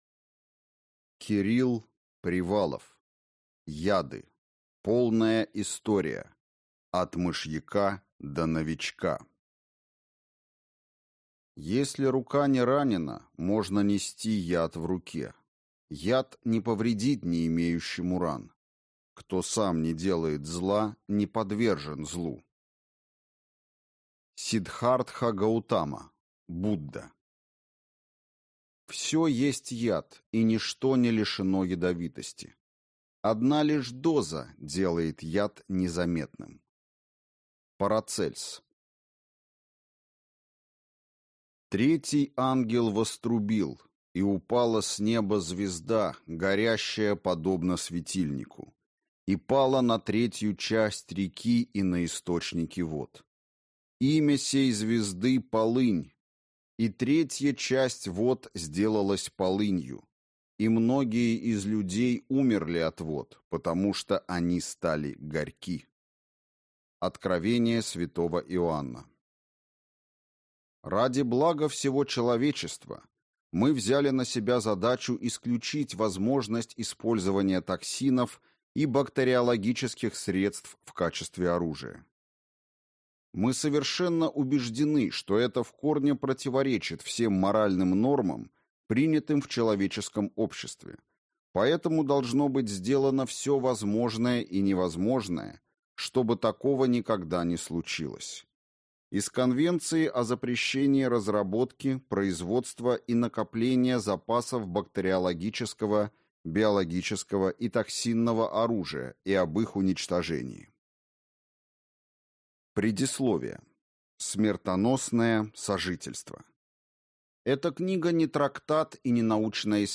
Аудиокнига Яды: полная история: от мышьяка до «Новичка» | Библиотека аудиокниг
Прослушать и бесплатно скачать фрагмент аудиокниги